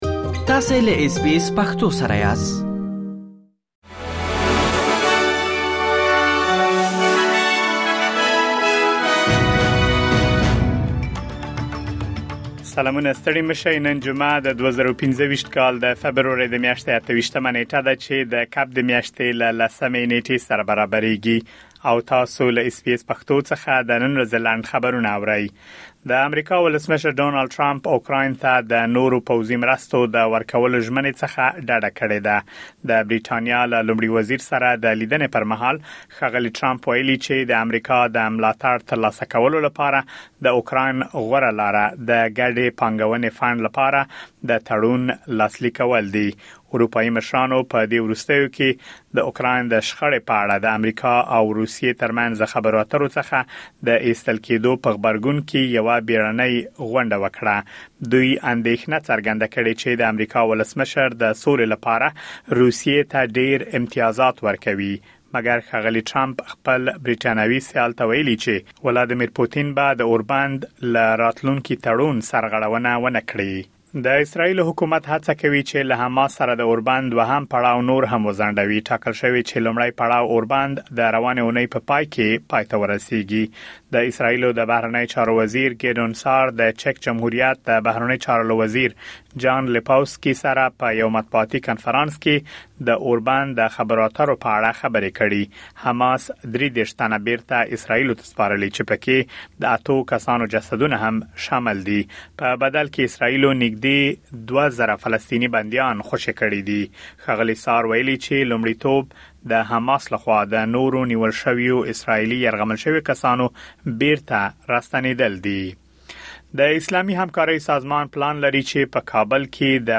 د اس بي اس پښتو د نن ورځې لنډ خبرونه | ۲۸ فبروري ۲۰۲۵